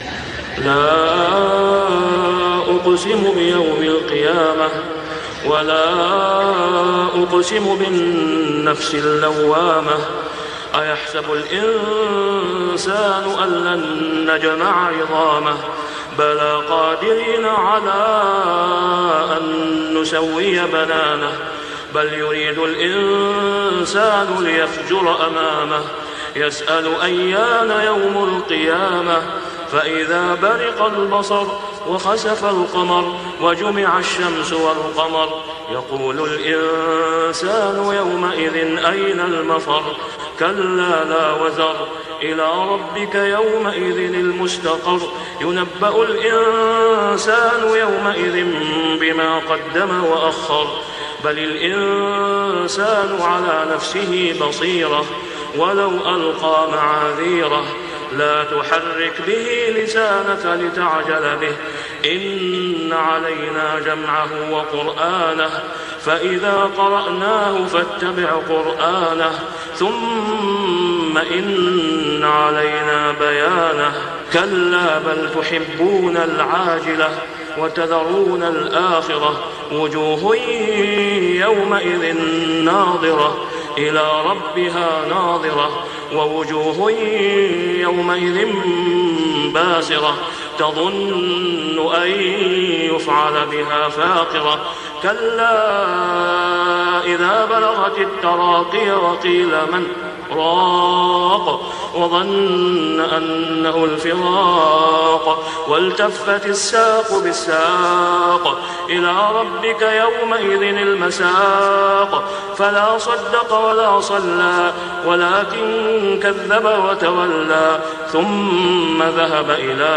سورة القيامة > السور المكتملة للشيخ أسامة خياط من الحرم المكي 🕋 > السور المكتملة 🕋 > المزيد - تلاوات الحرمين